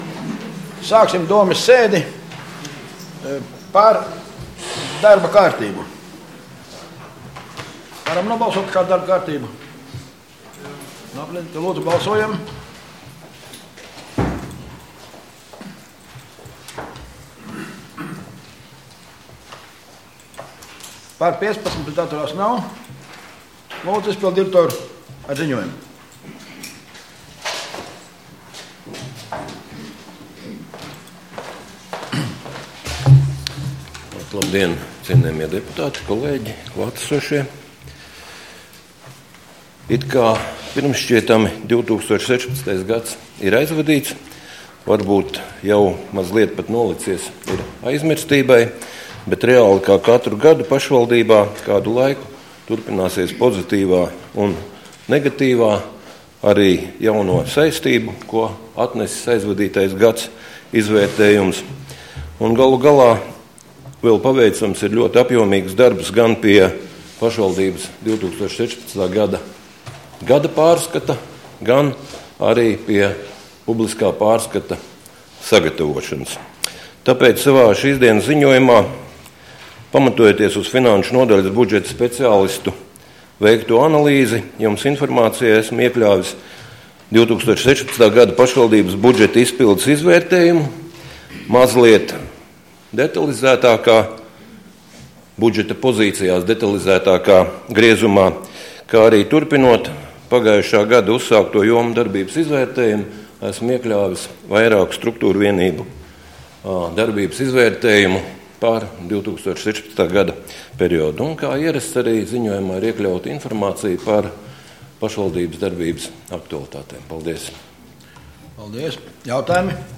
Domes sēde Nr. 3